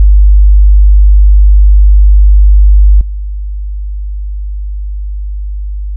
基準音2秒 → 透過損失された音2秒 を聞くことができます。
50Hzの音データ/基準音と低減音 [自動車の低音マフラーなど] 80Hzの音データ/基準音と低減音 [いびきや大型犬の鳴き声など] 100Hzの音データ/基準音と低減音 [会話音[男性の声]など]